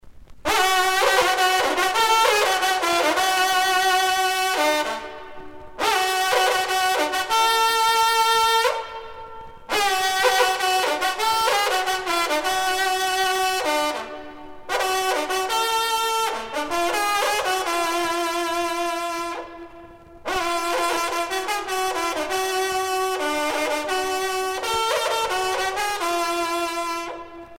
trompe - fanfare - lieux-dits
circonstance : vénerie